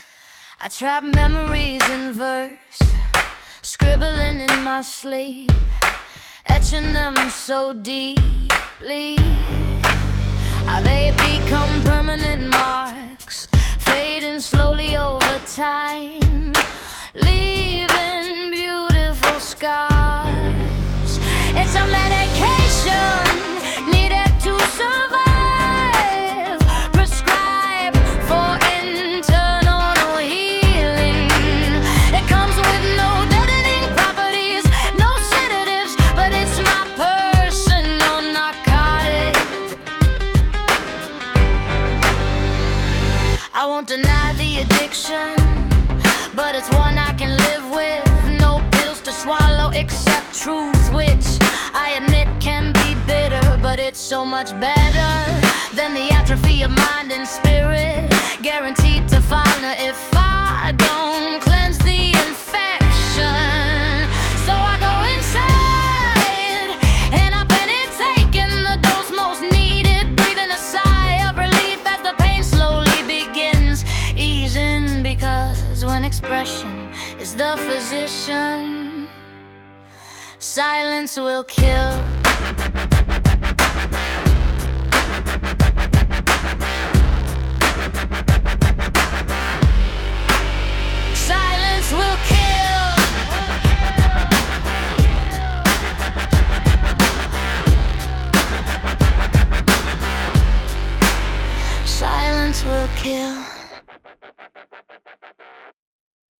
Poetry
Infectious vibe your words and the musical arrangement my friend.